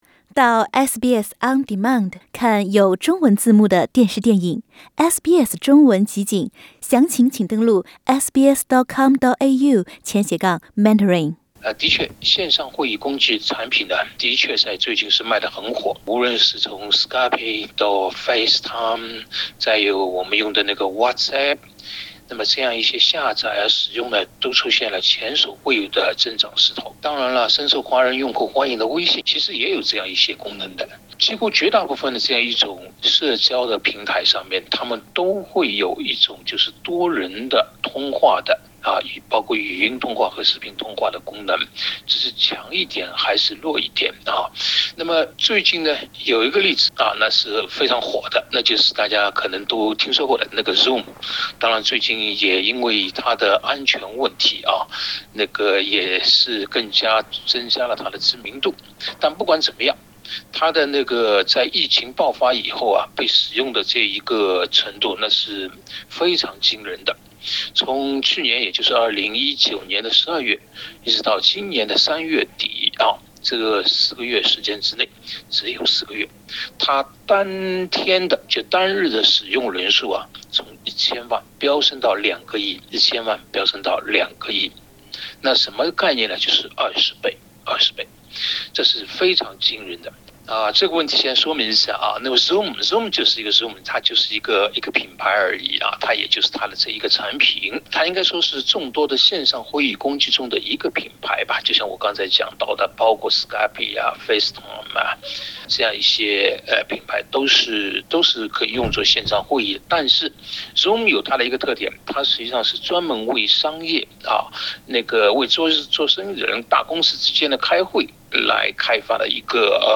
（本节目为嘉宾观点，仅供参考。）